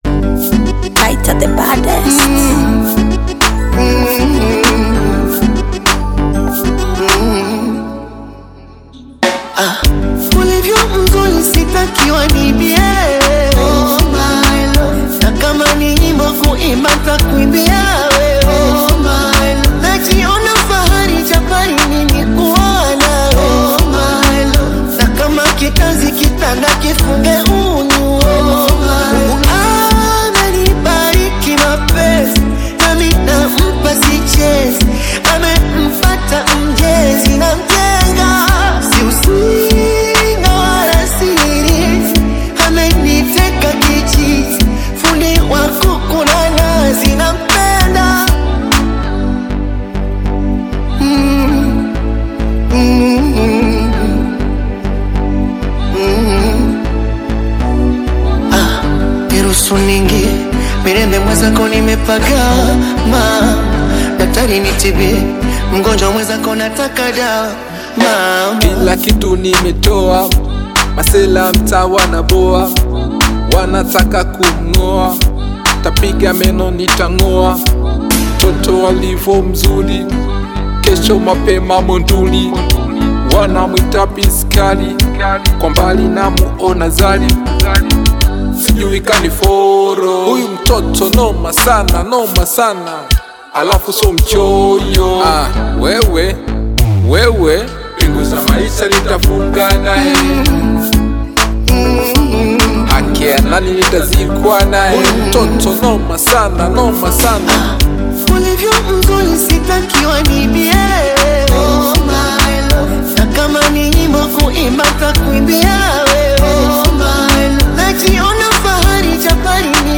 romantic Bongo Flava single
rich vocal tone
engaging rhythm